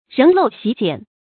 仍陋袭简 réng lòu xí jiǎn 成语解释 因陋就简。谓凭借原有的简陋条件办事。